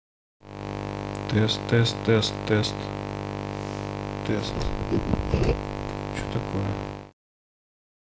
Сильный шум dbx376 + октава102
Шум появляется не сразу.
Может кто-то по характеру шума поймет что это?